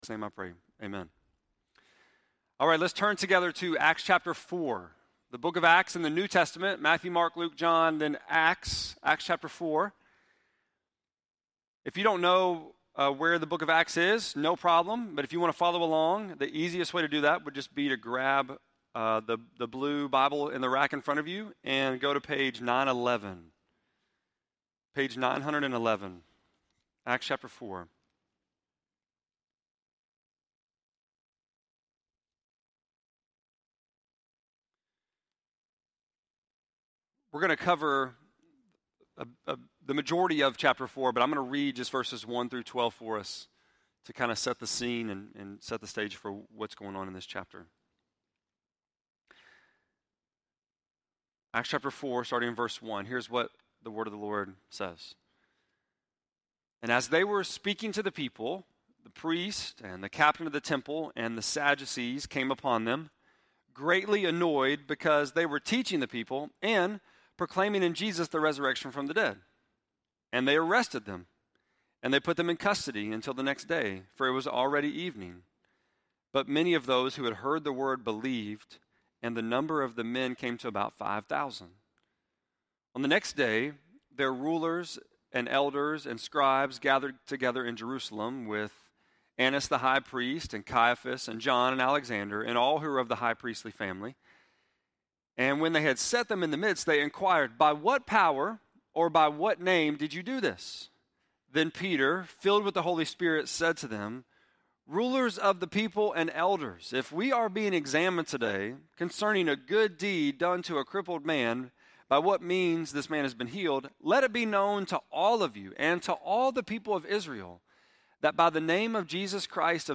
6.23-sermon.mp3